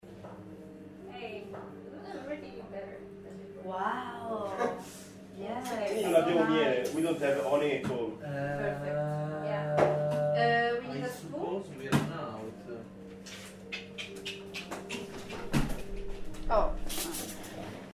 part2-27_group1-encouragng sound.mp3